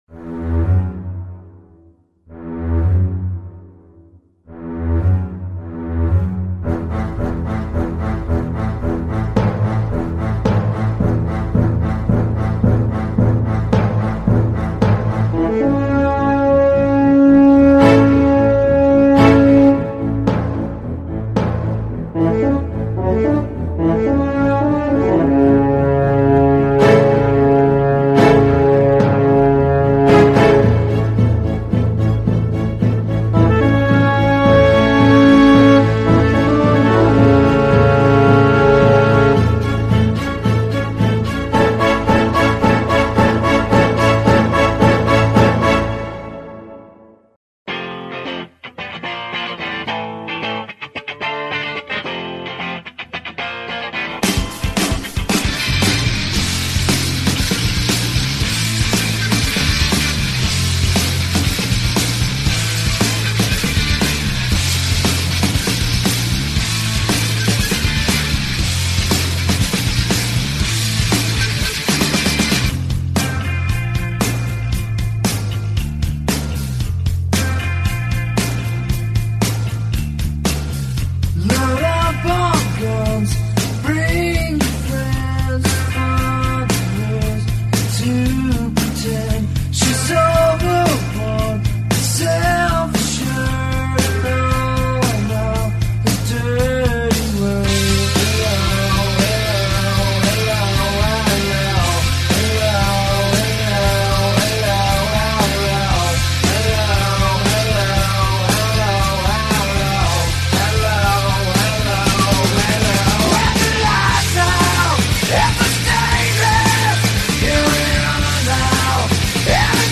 [Also transcribed from minor to major key.]